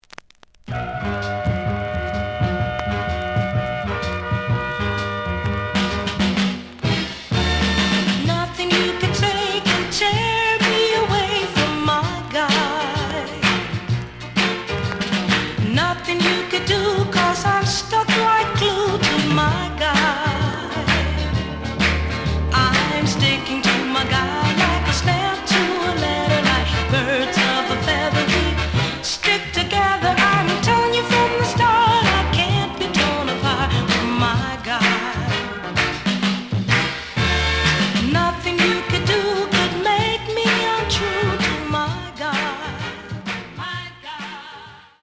The audio sample is recorded from the actual item.
●Genre: Soul, 70's Soul
Slight edge warp.